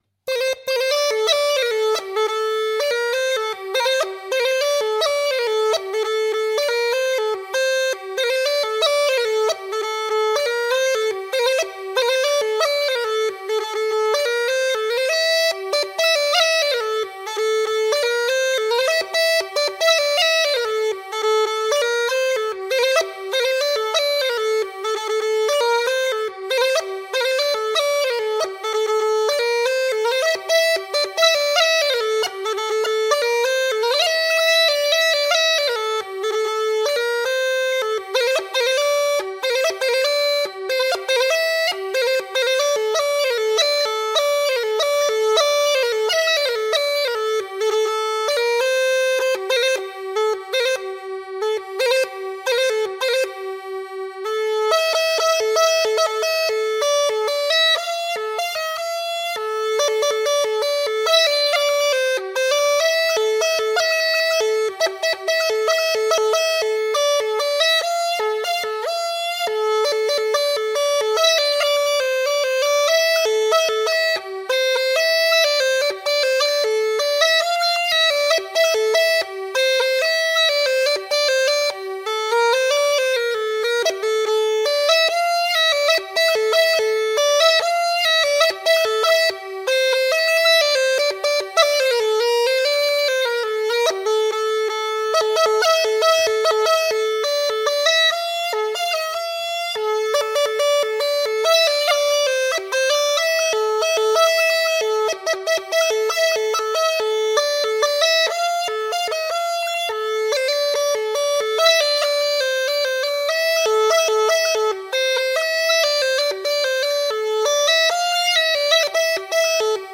تمپو ۹۵ دانلود